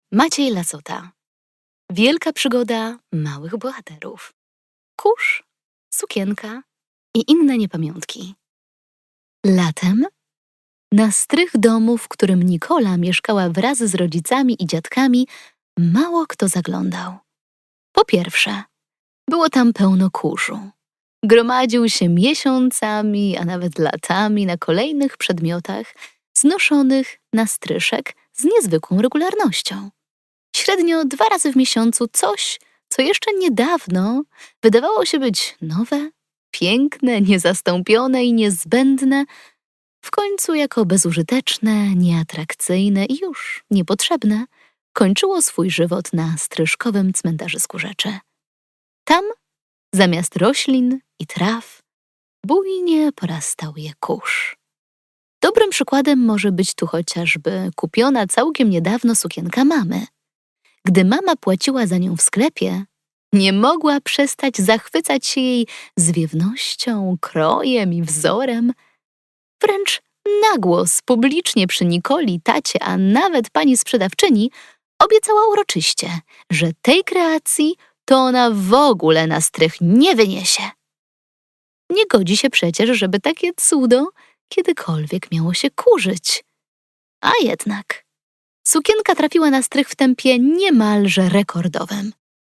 Wykonuję również dubbing do gier i animacji.